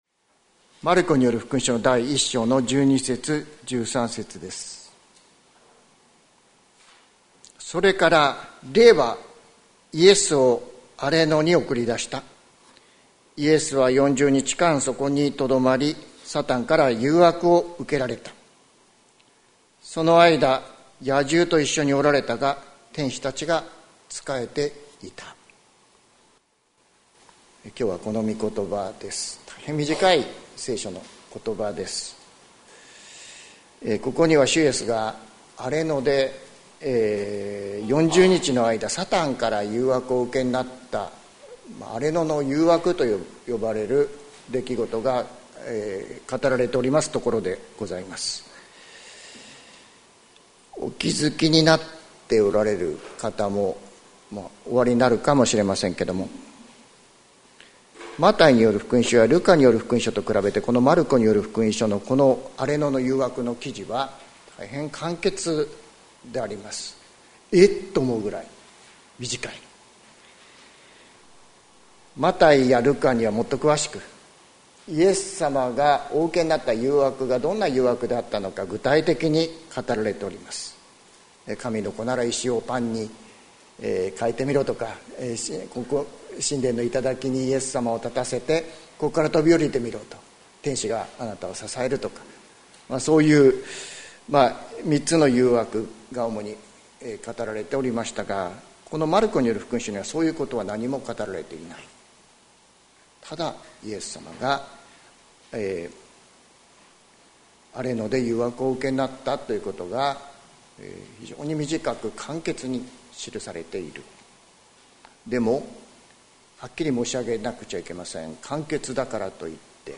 関キリスト教会。説教アーカイブ。